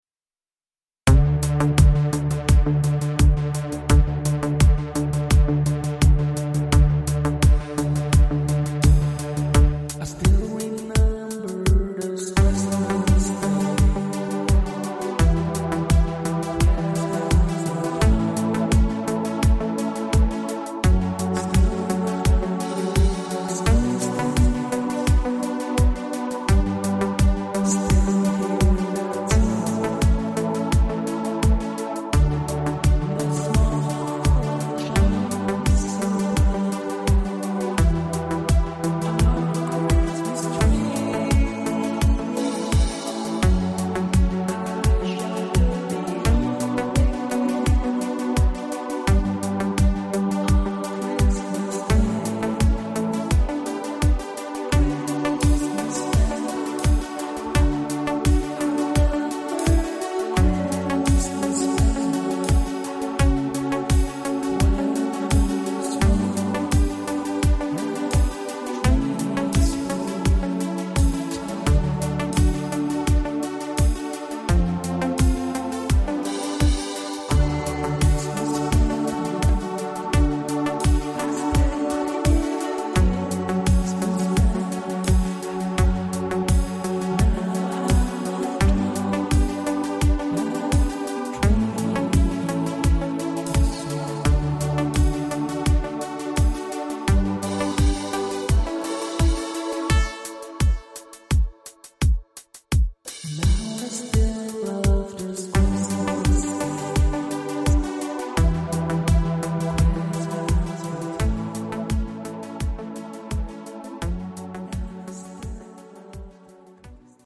Da ich so bin, wie ich bin, wird dann oft Synth-Pop draus und meine grössten Helden, die Pet Shop Boys, feiern gerade ihr 40jähriges.
ausgefaded bei der zweiten Strophe
Der Take ist nicht richtig gemixt, es war nur ein Versuch mit der Gesangsspur und meine Stimme ist halt meine Stimme ... das Foto zur Demo-Version zeigt mich übrigens im Kindesalter zur Christmas-Zeit at Home.